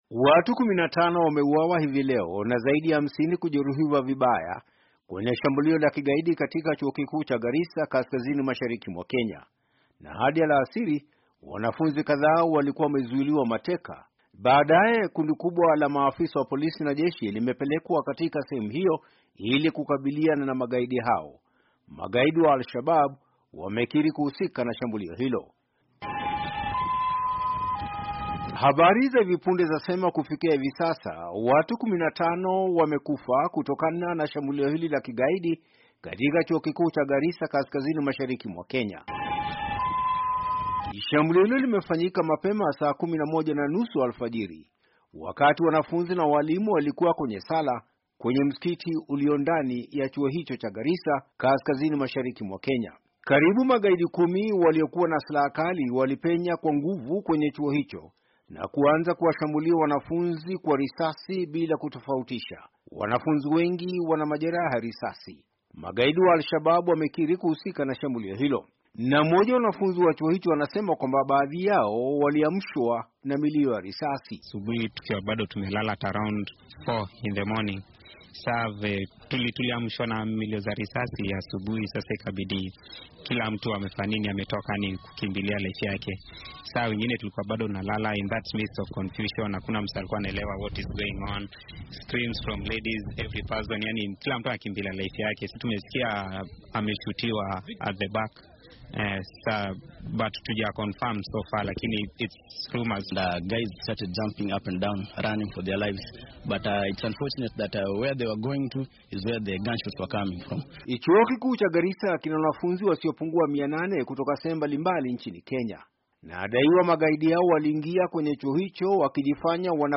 by VOA Swahili – Idhaa ya Kiswahili ya Sauti ya Amerika